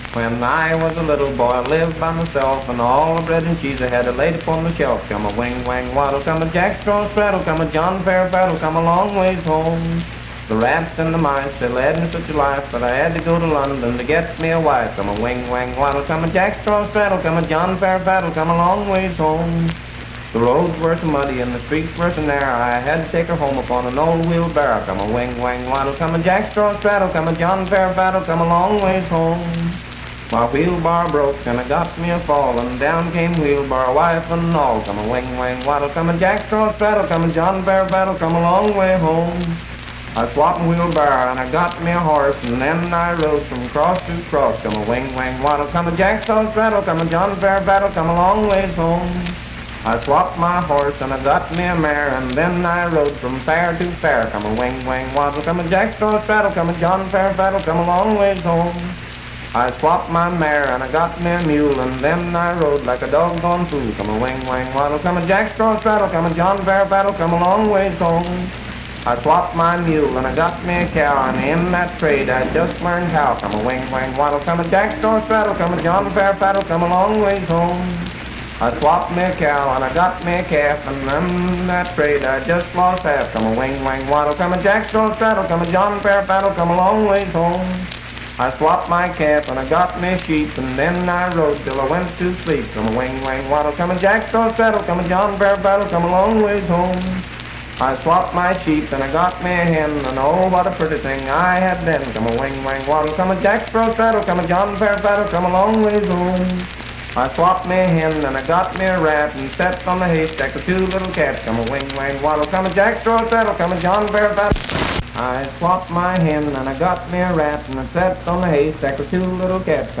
Unaccompanied vocal performance
Dance, Game, and Party Songs
Voice (sung)
Roane County (W. Va.), Spencer (W. Va.)